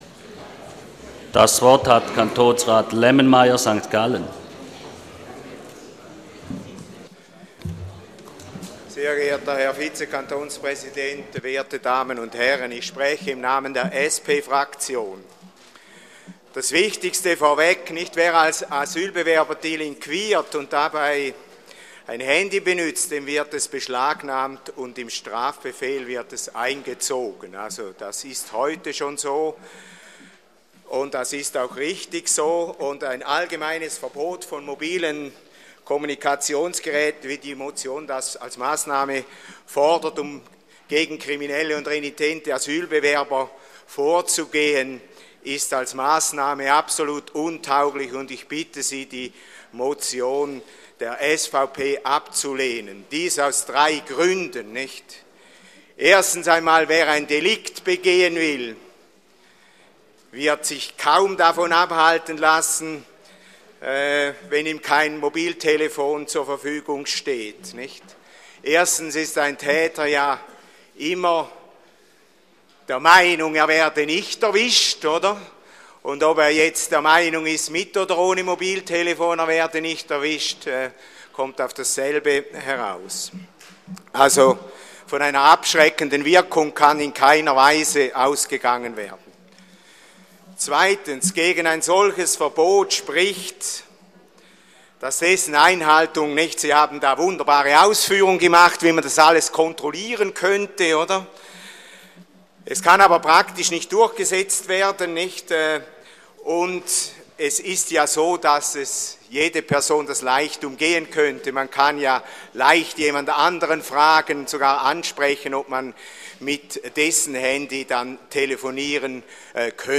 Session des Kantonsrates vom 25. bis 27. Februar 2013